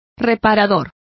Complete with pronunciation of the translation of repairman.